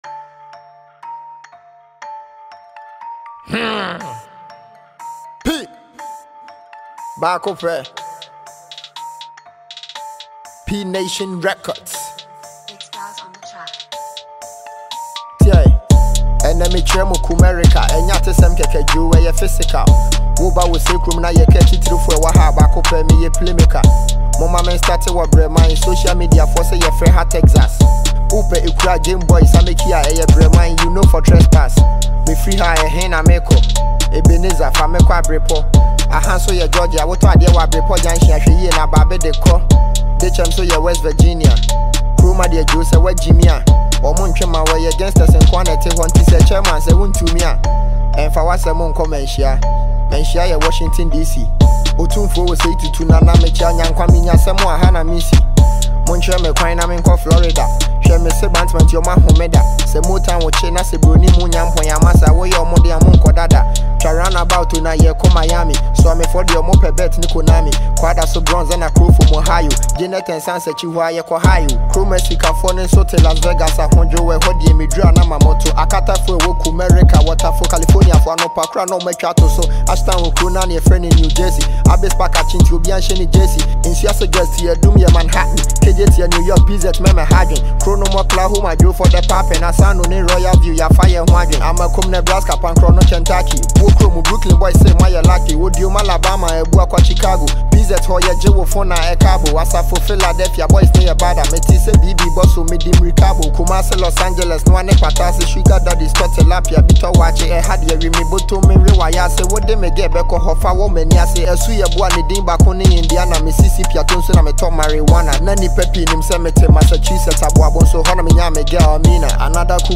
Enjoy this new Ghana mp3 music